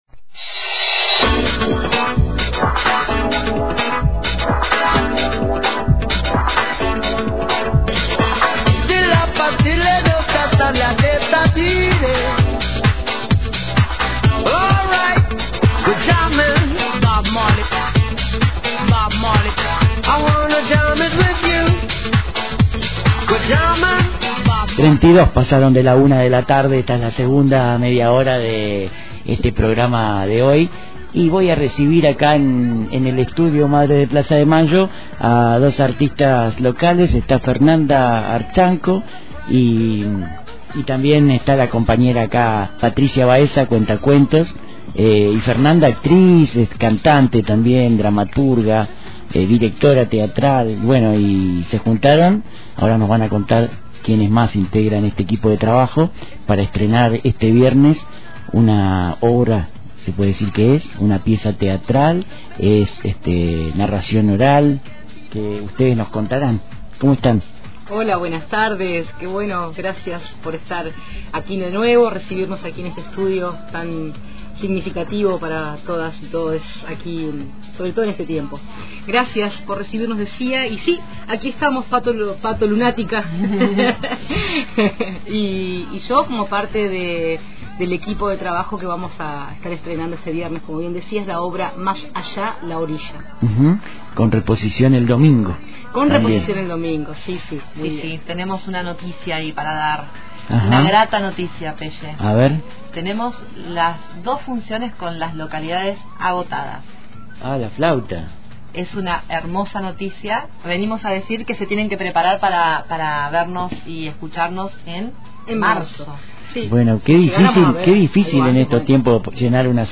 «Más Allá, La Orilla» es una pieza teatral creada a partir de escritos de varixs autorxs de aquí y de allá. En la radio charlamos